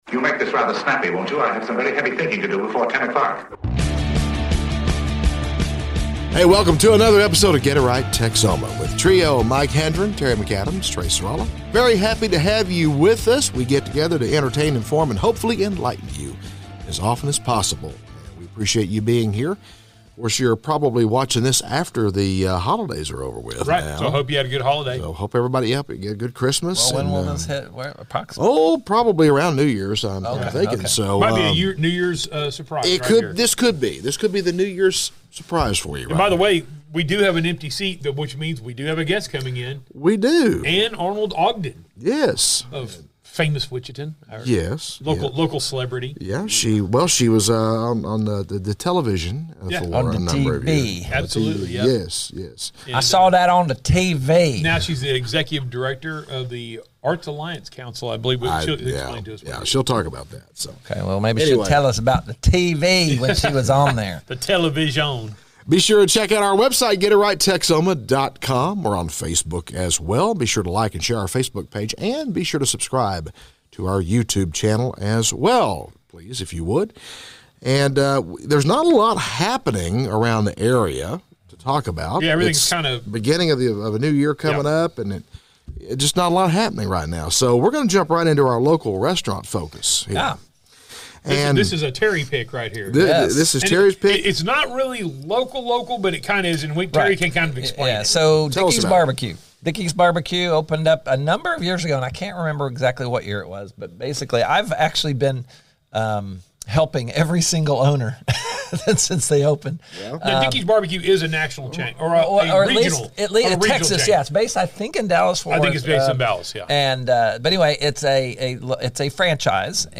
😂 Friendly Banter: The crew hilariously debates local hot spots, discusses intriguing scams, and dives into some political chatter.